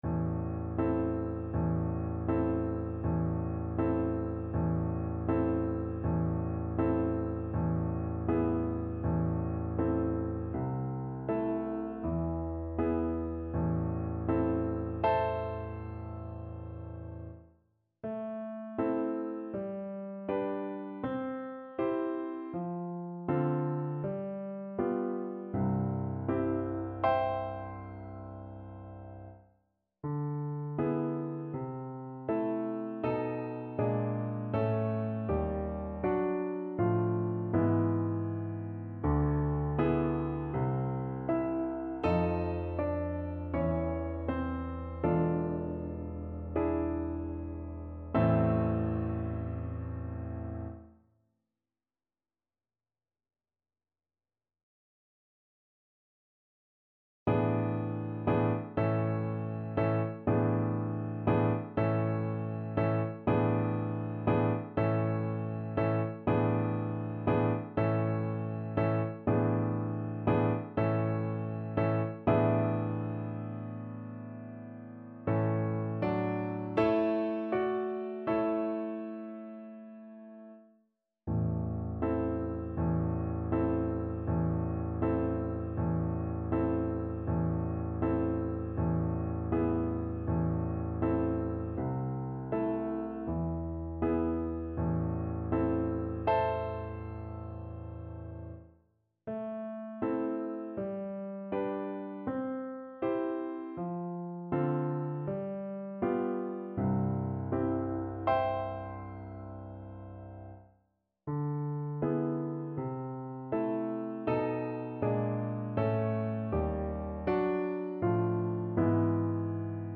4/4 (View more 4/4 Music)
Un poco andante
A minor (Sounding Pitch) (View more A minor Music for Oboe )
Classical (View more Classical Oboe Music)